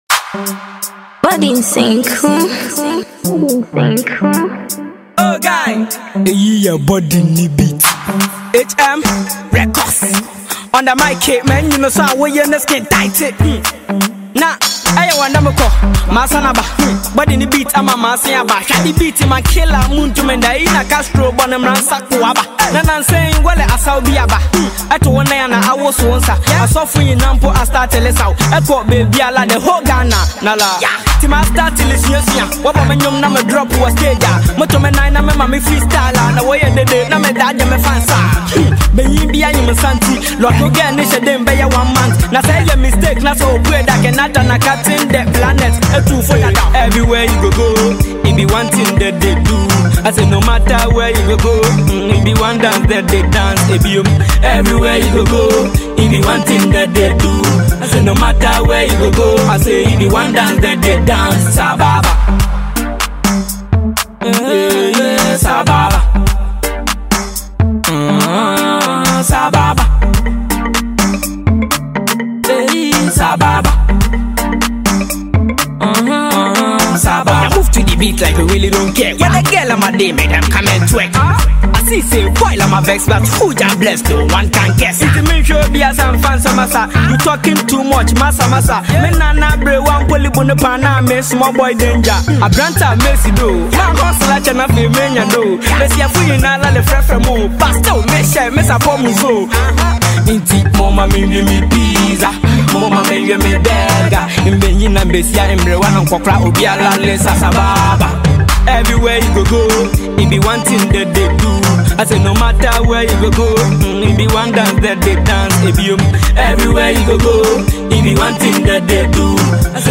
Ghana Music Music
Ghanaian Talented Rapper